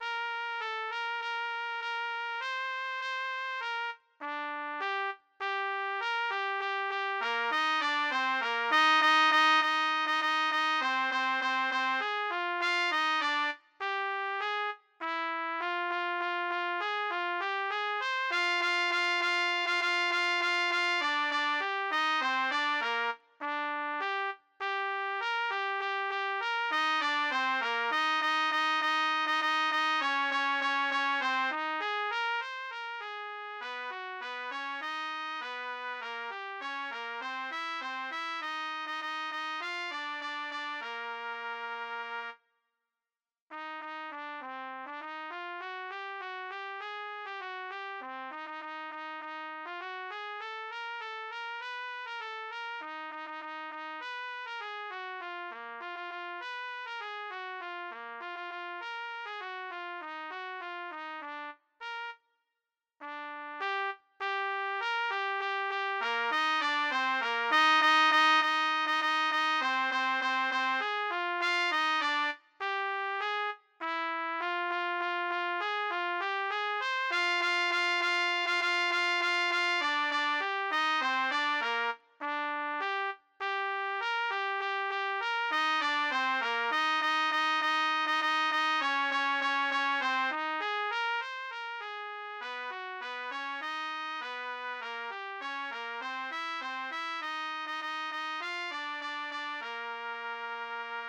D=Counter-melody/Harmony/Bass Part-for intermediate to experienced players